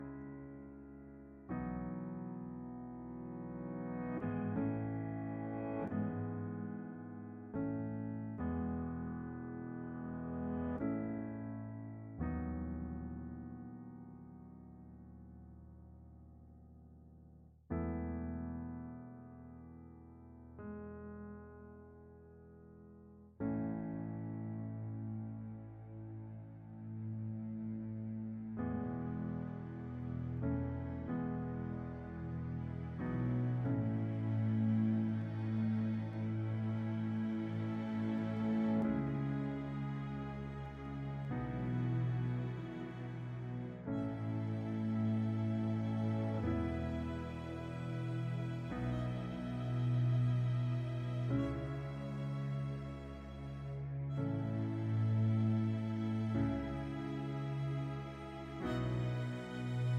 no Backing Vocals Musicals 5:51 Buy £1.50